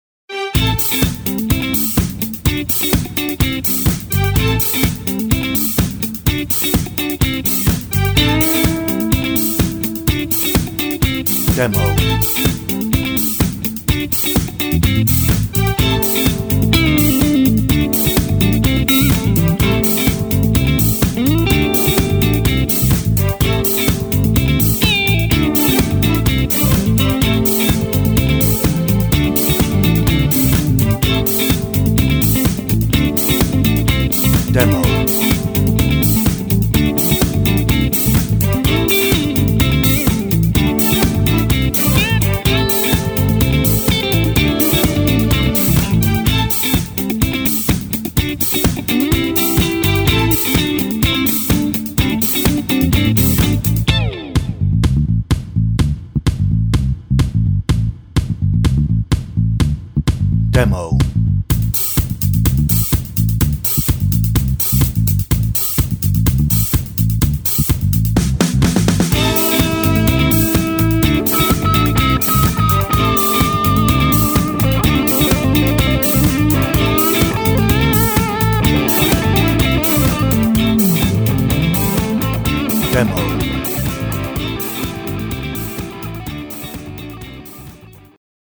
Instrumental Instrumental (BGV)